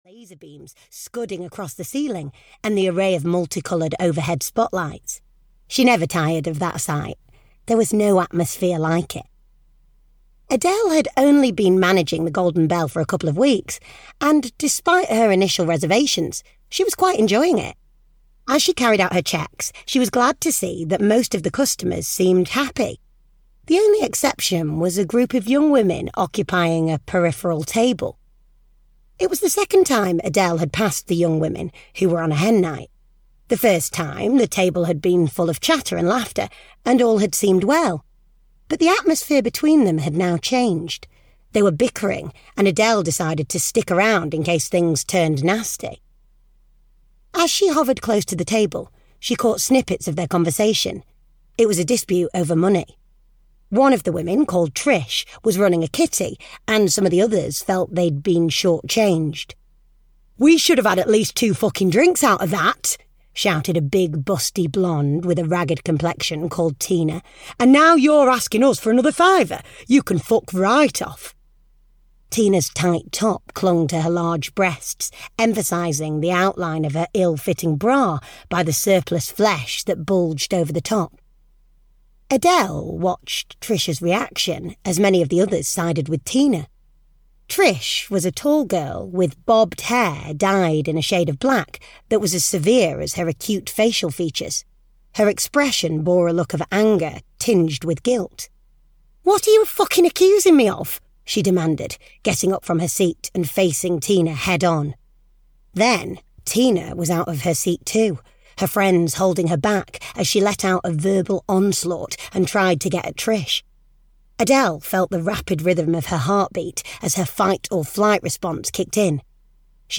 Vendetta (EN) audiokniha
Ukázka z knihy